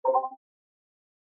warning3.mp3